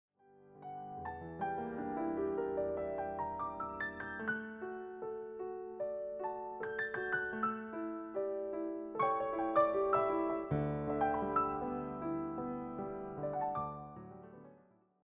all reimagined as solo piano pieces.
just the piano, no vocals, no band.